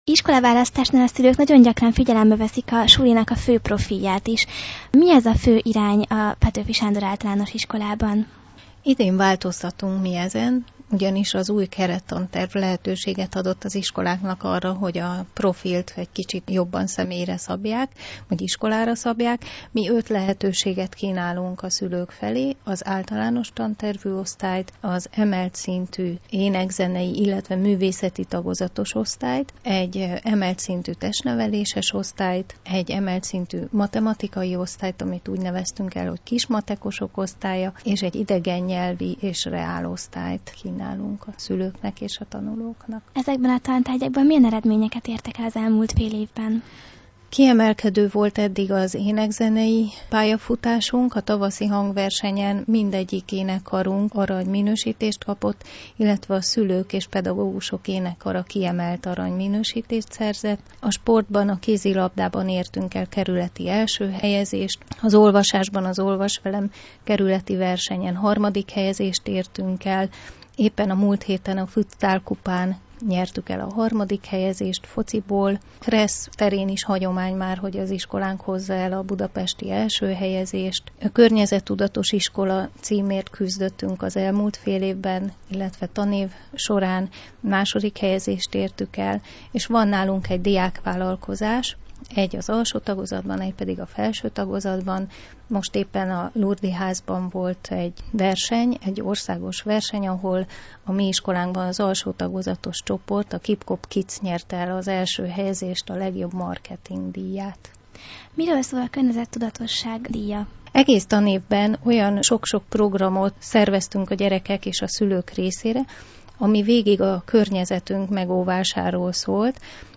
interjúban